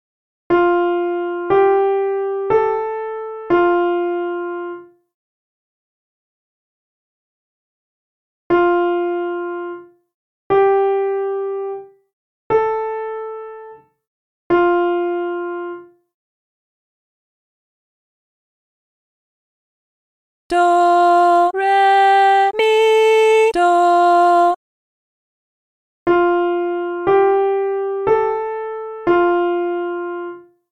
The melodies are short and each will start and end on the tonic (do).
If you need a hint, listen to the hint clip which will play the melody more slowly and then reveal the solfa syllables.
(key: F Major)